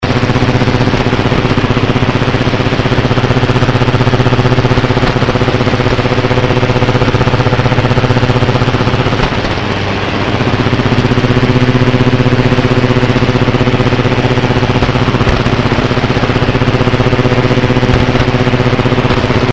полностью как пеленой закрыло и пионер и тройку рычащей помехой,